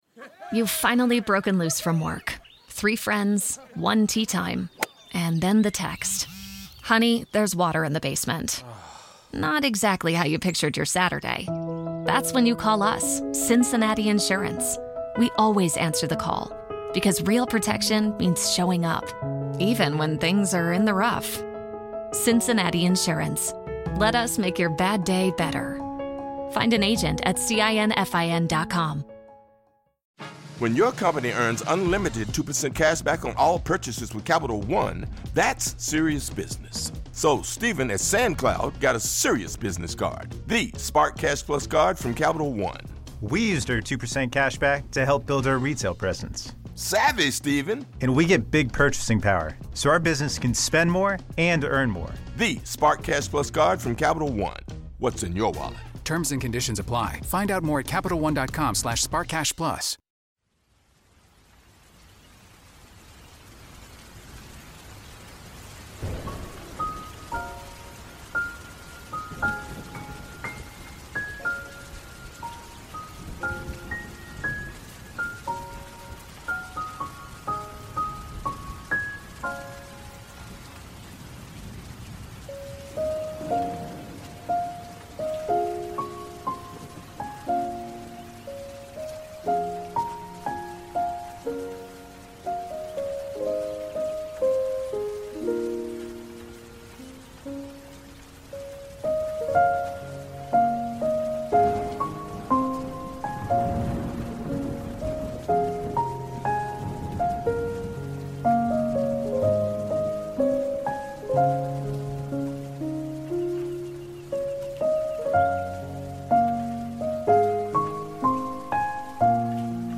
Ambient Ocean Waves and Soothing Wind – Evening Wind Mix for Emotional Relief for Meditation and Healing
Each episode of Send Me to Sleep features soothing soundscapes and calming melodies, expertly crafted to melt away the day's tension and invite a peaceful night's rest.